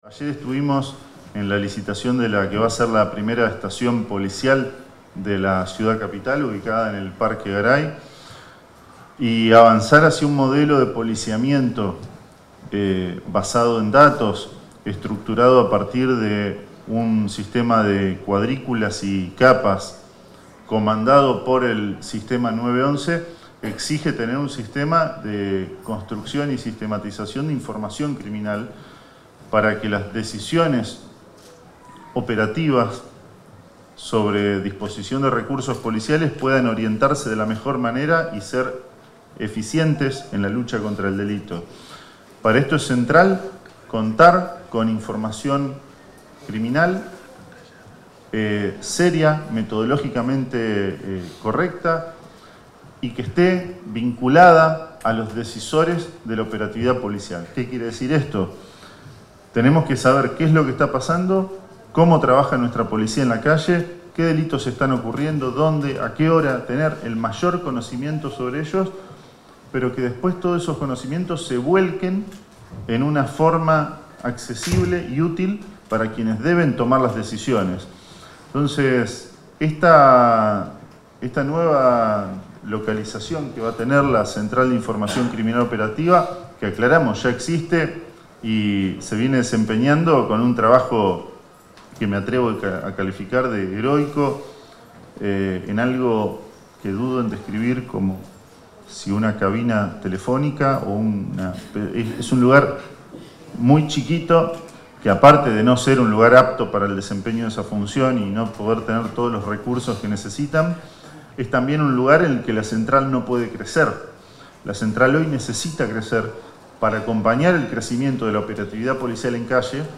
Fragmento de la exposición del ministro Cococcioni